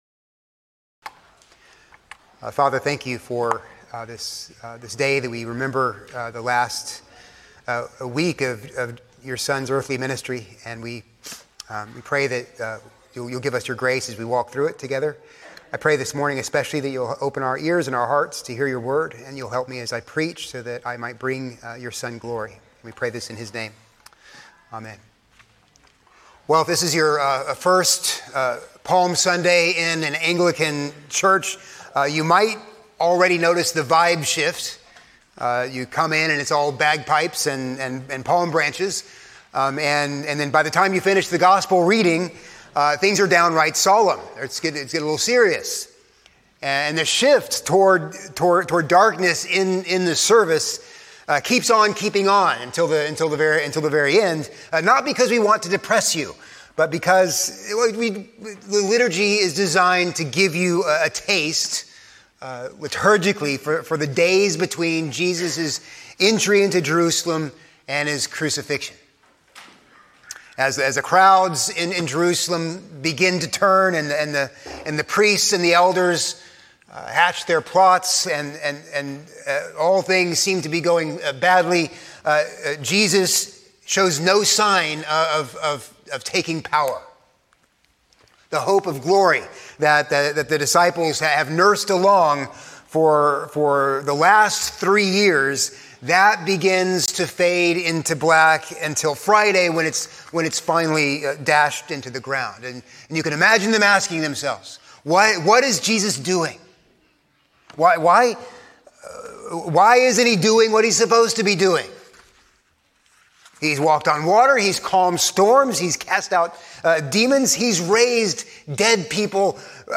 A sermon on Luke 23:32-43